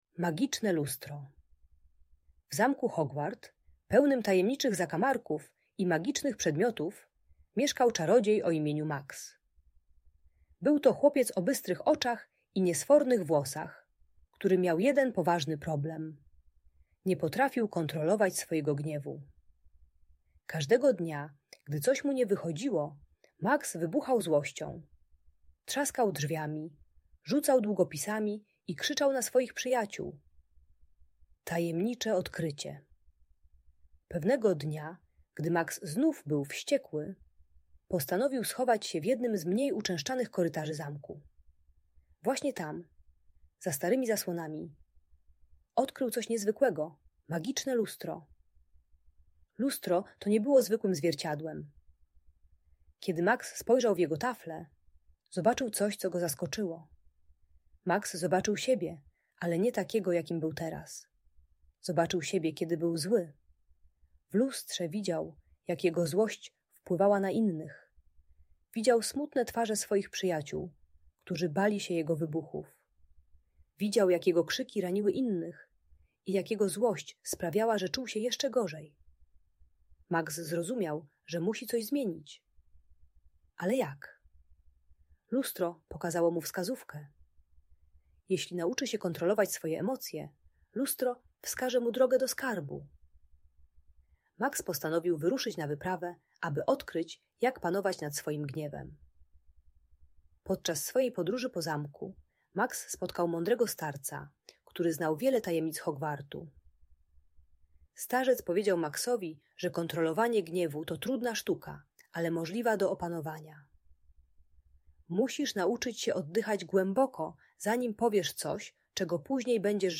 Magiczne Lustro - Audiobajka